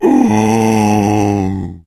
fracture_die_0.ogg